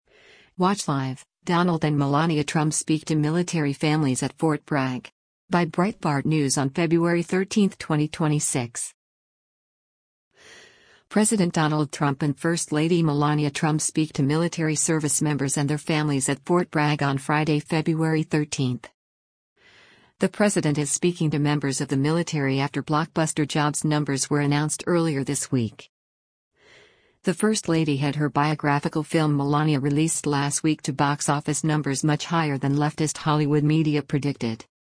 President Donald Trump and First Lady Melania Trump speak to military servicemembers and their families at Fort Bragg on Friday, February 13.